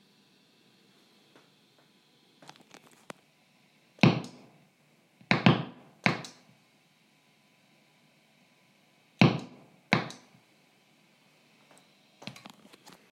Lautes Knackgeräusch bei Betätigen des Tuners (Polytune) und Looper (Ditto); Audiofile angehängt
Hallo Leute wenn ich meinen Tuner oder Ditto Looper einschalte, tritt ein lautes Knackgeräusch auf. Ich spiele mittels eines Lehle Dual über 2 Amps - einen Tone King Imperial und über einen Marshall DSL 40C + 4x12 Box.